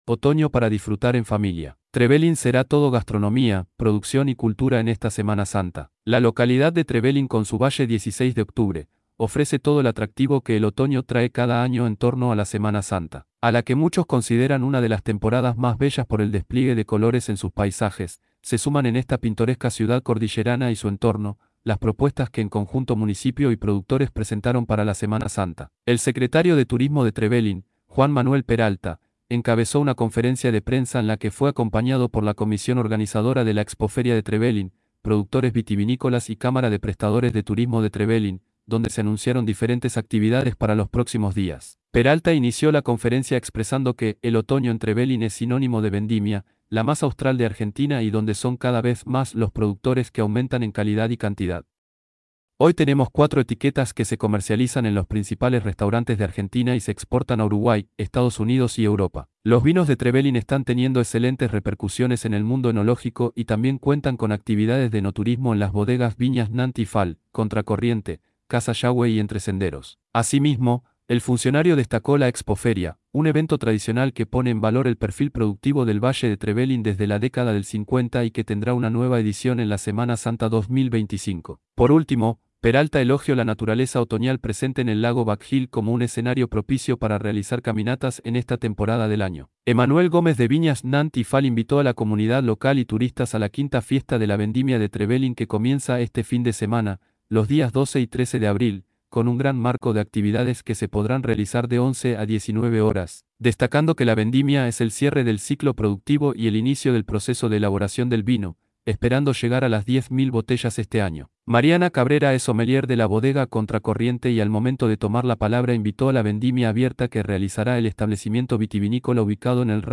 El secretario de Turismo de Trevelin, Juan Manuel Peralta, encabezó una conferencia de prensa en la que fue acompañado por la comisión organizadora de la Expo-Feria de Trevelin, productores vitivinícolas y Cámara de Prestadores de Turismo de Trevelin, donde se anunciaron diferentes actividades para los próximos días.
conferencia_de_prensa_actividades_en_semana_santa_trevelin.mp3